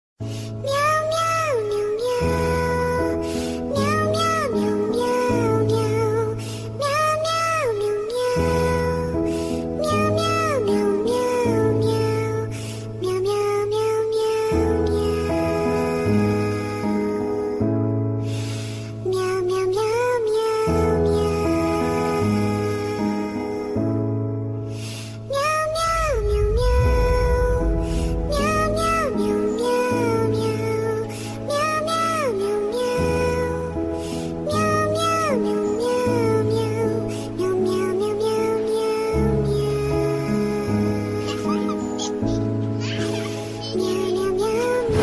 Meow Meow Meow Meow Sound Effect Free Download
Meow Meow Meow Meow